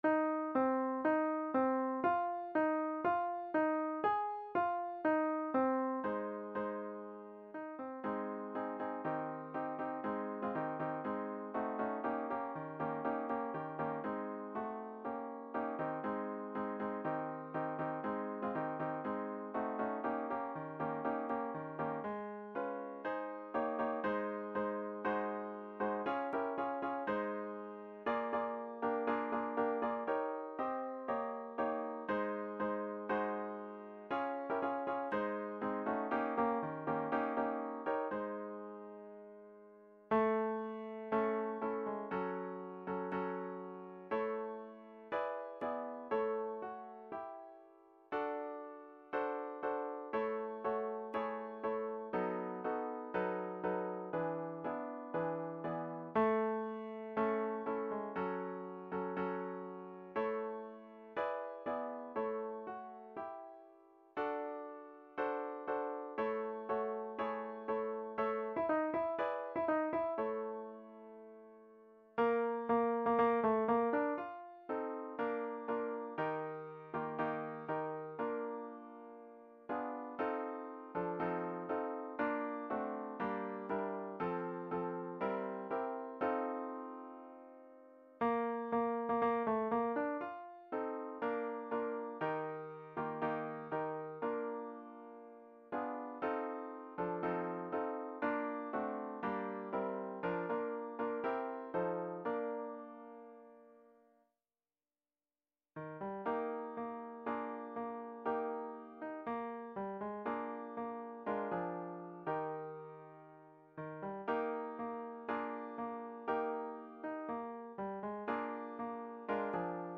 Music and learning Tracks
All parts at “120”